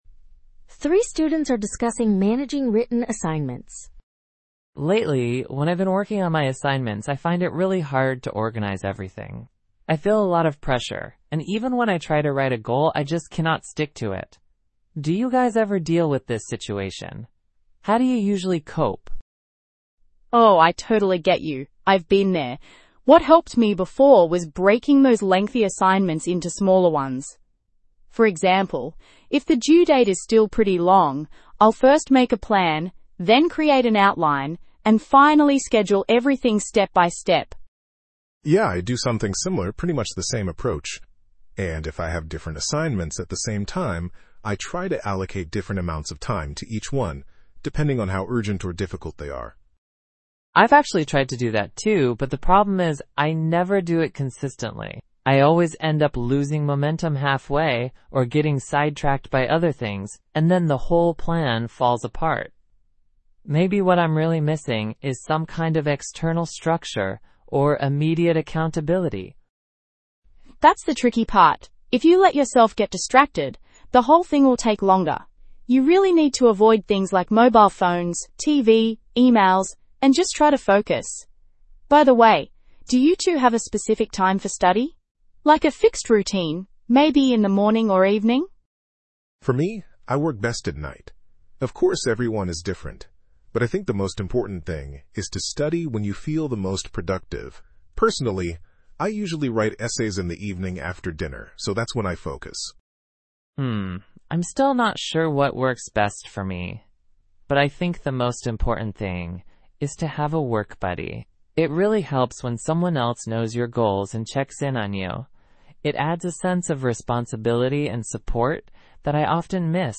PTE Summarize Group Discussion – Management of Assignment